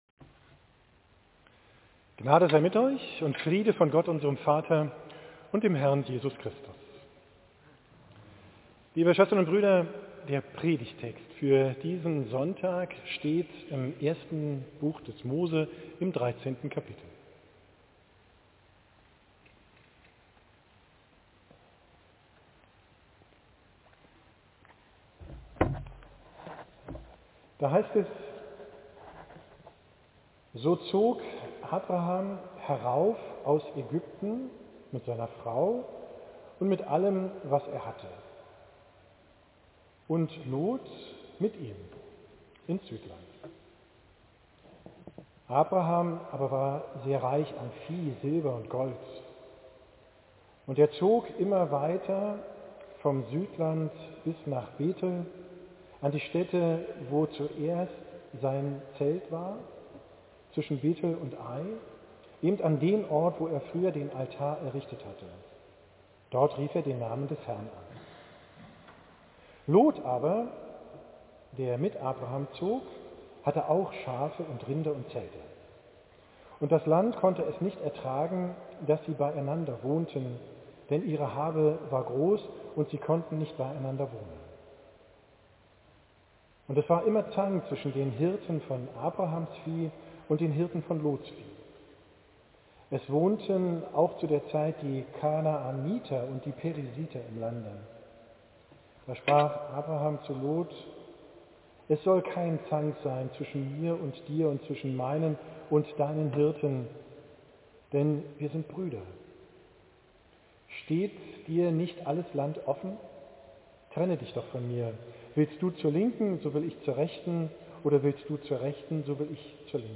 Predigt vom 21. Sonntag nach Trinitatis, 29.